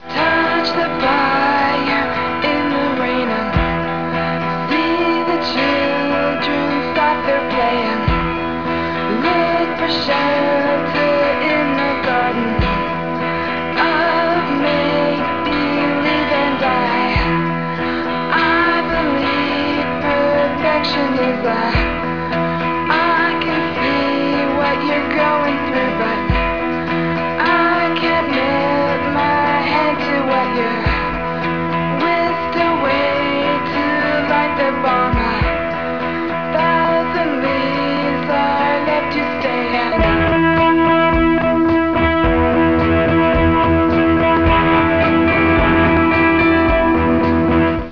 noise-guitar innovators and post-punk prodigies